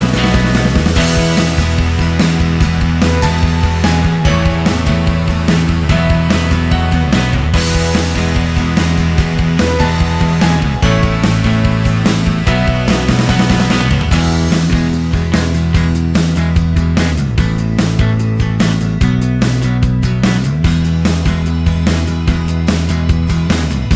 No Solo Guitars Rock 4:27 Buy £1.50